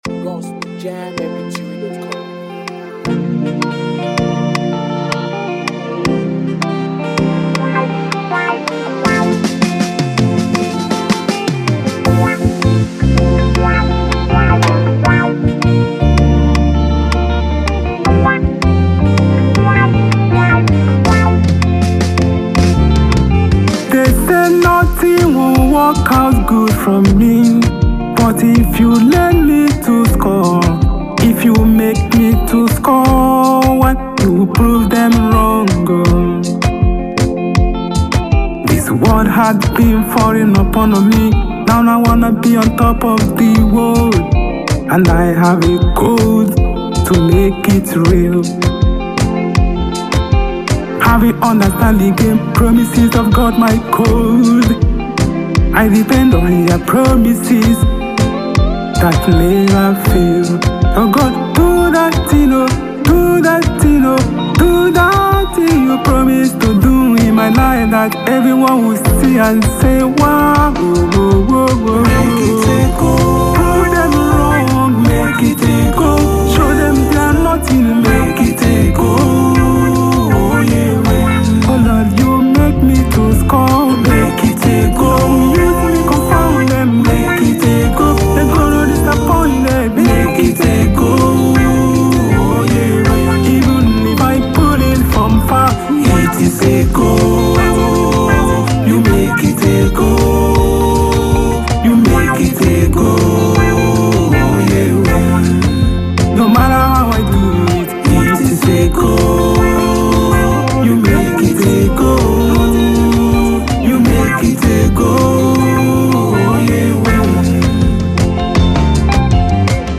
Fast gospel singer